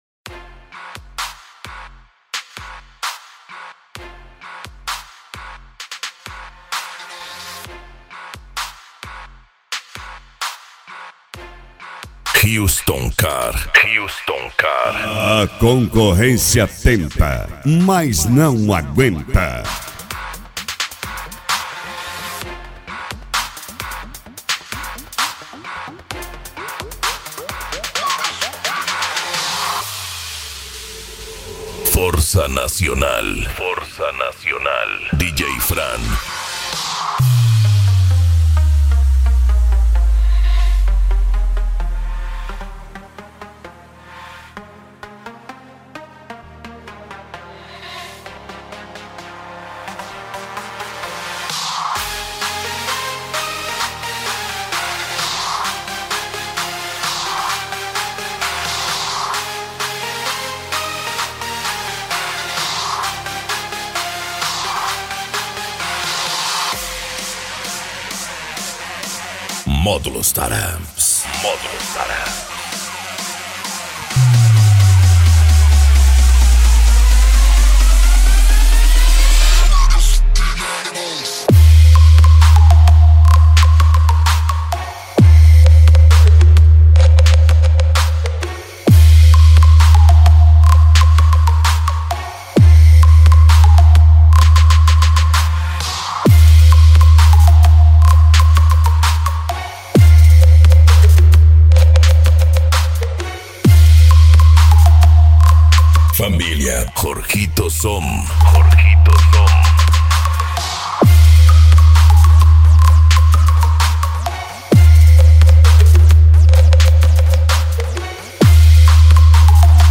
Bass
Cumbia
Funk
Musica Electronica
Remix